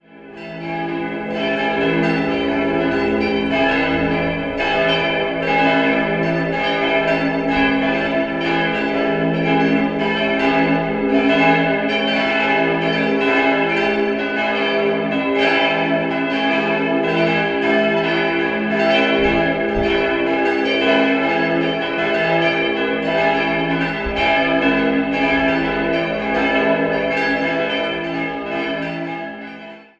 5-stimmiges Geläut: e'-gis'-h'-cis''-e'' Die Glocken 3 und 4 wurden 1782 von der Gießerei Bändl in Amberg gegossen, Nr. 2 und 5 sind Werke aus dem Jahr 1950 von Karl Hamm (Regensburg).